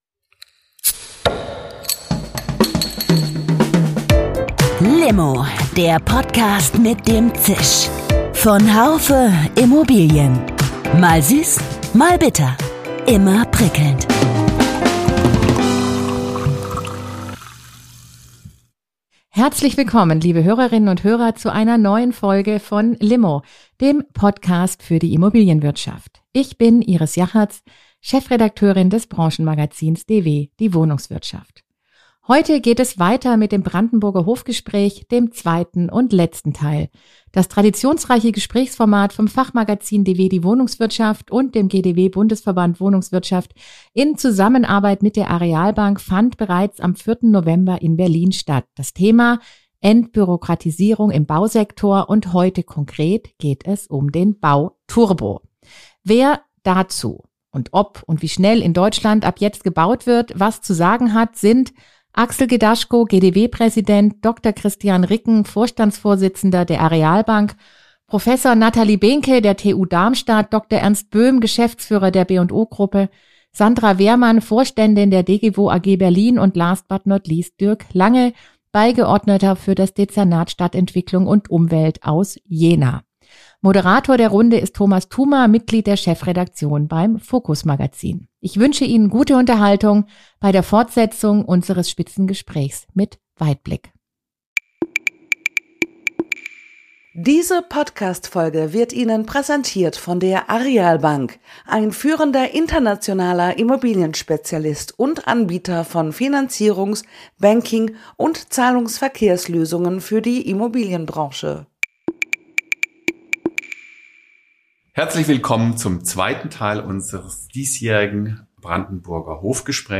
Beim 32. Brandenburger Hof Gespräch dreht sich alles um die Entbürokratisierung des Bausektors. Sechs Entscheidungsträger aus Wohnungswirtschaft, Wissenschaft, Bauwirtschaft, Bankenwesen und Verwaltung diskutierten wie man Wohnraummangel und fehlende Investitionen begegnen könnte.